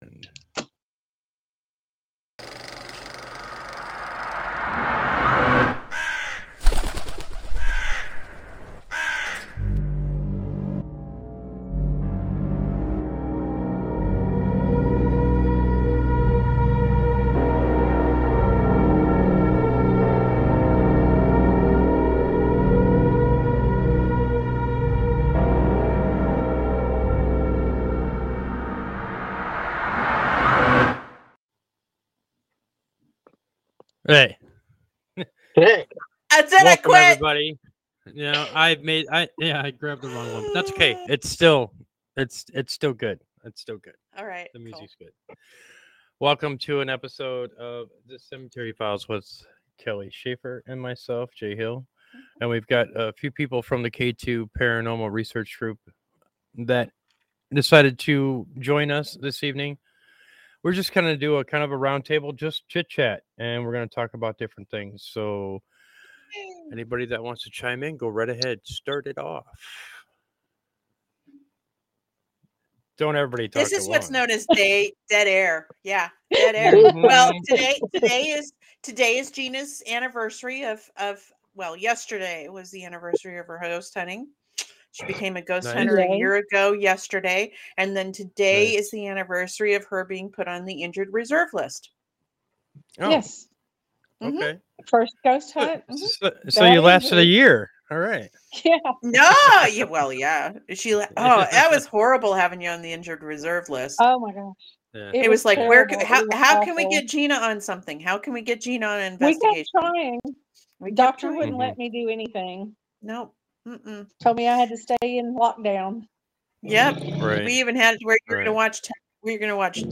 The Cemetery Files Podcast welcomes K2 Paranormal Research With a small round table discussion as well as mentioning the Gettysburg documentary fundraising.